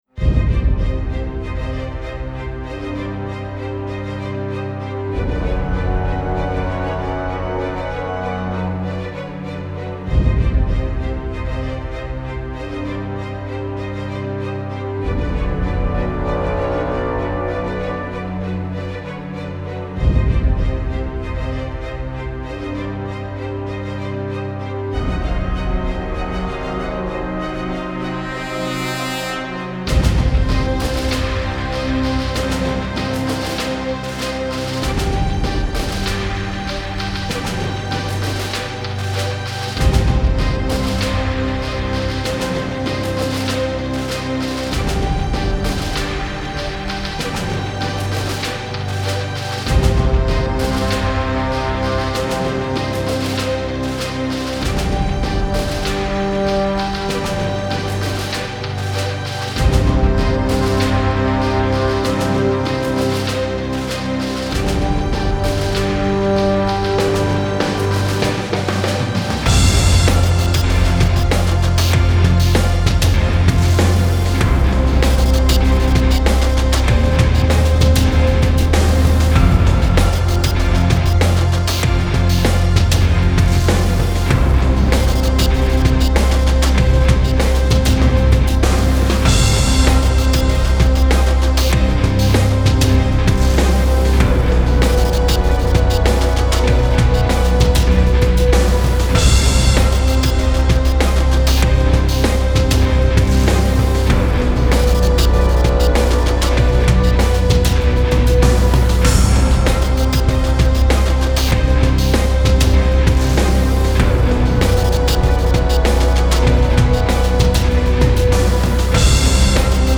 Style Style Rock, Soundtrack
Mood Mood Epic, Intense
Featured Featured Brass, Choir, Drums +2 more
BPM BPM 97